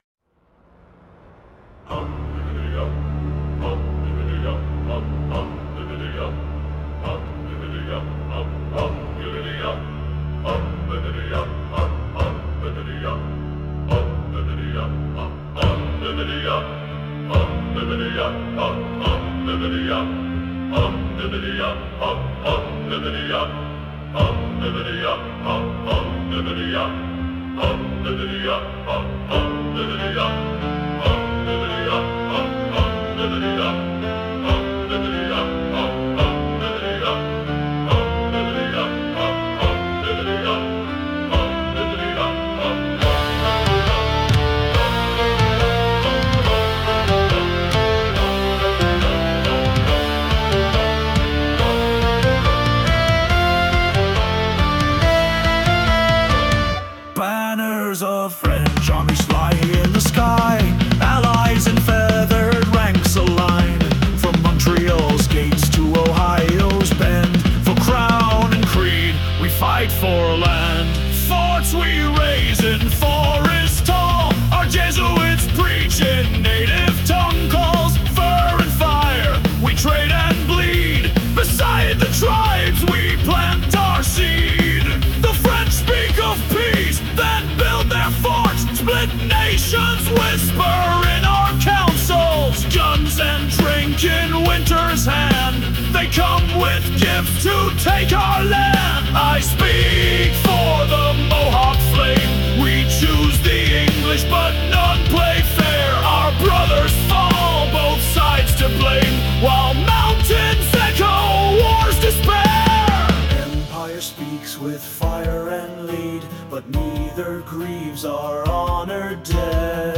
blistering, sorrowful, and unrelenting musical chronicle